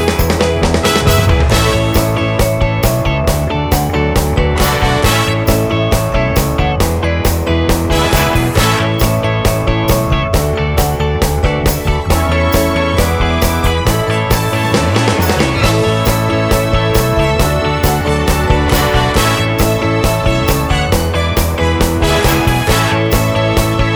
No Bass Pop (1970s) 3:20 Buy £1.50